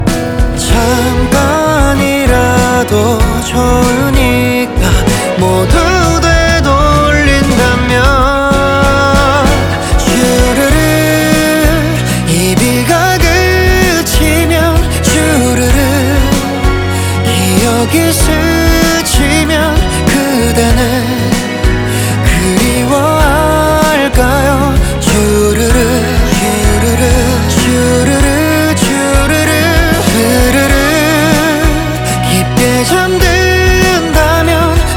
Жанр: Поп музыка
K-Pop, Pop